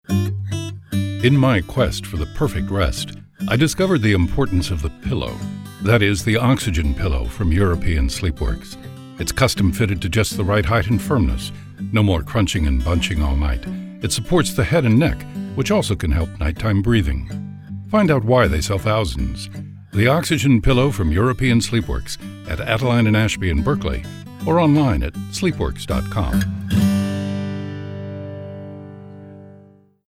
• Campaign utilizes key sonic identifiers including the unique and recognizable voice, paired with a consistent music style for its radio commercials that have led to lifts in brand awareness, traffic and sales.